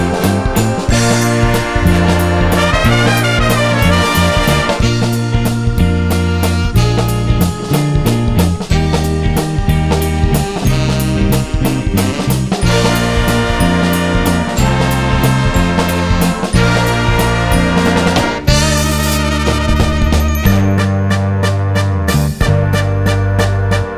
Live Version Pop (1960s) 4:11 Buy £1.50